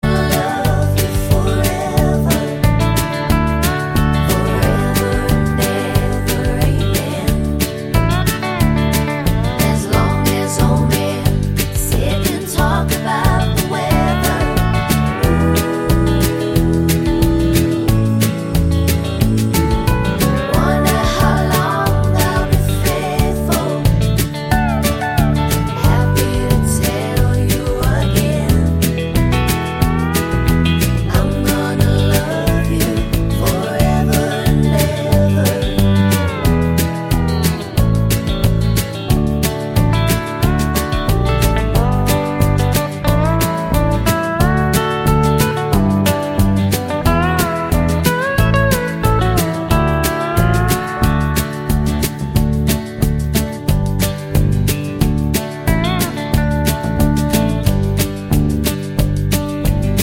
no Backing Vocals Country (Male) 3:33 Buy £1.50